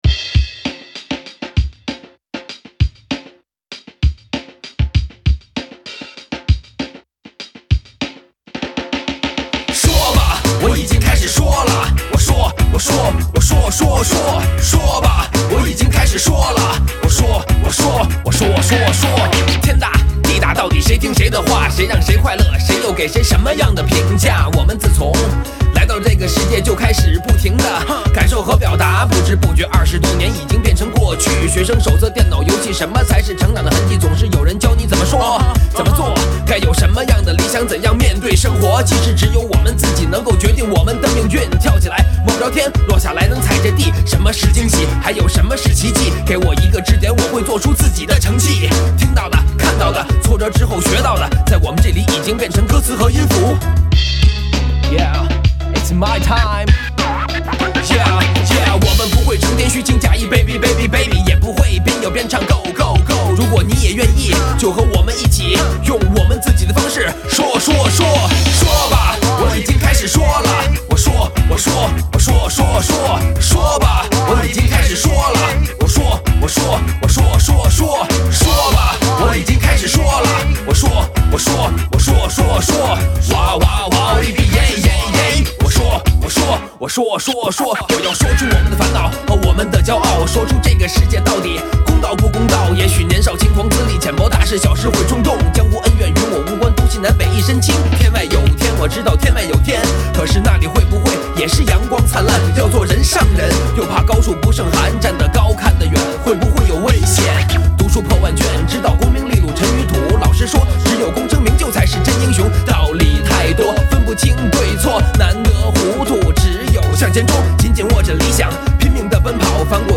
自省而悲壮的